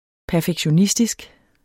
Udtale [ pæɐ̯fəgɕoˈnisdisg ]